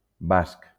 wymowa:
IPA[ˈbask]